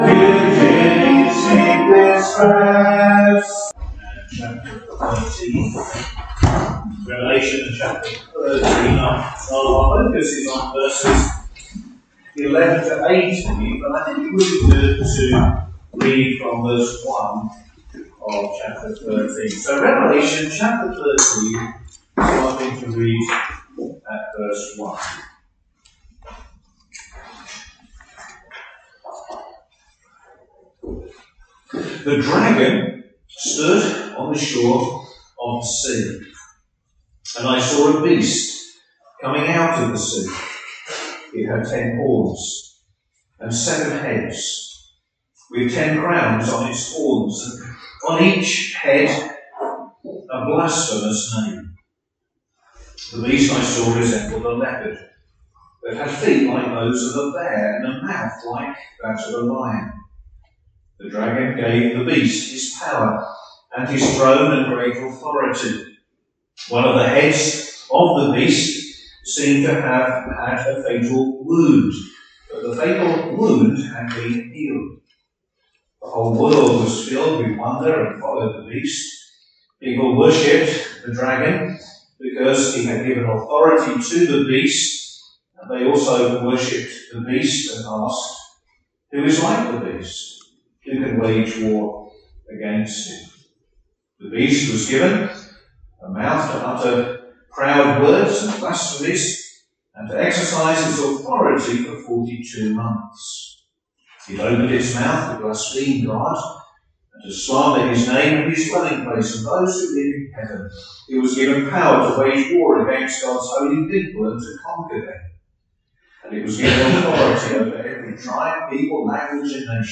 Service Evening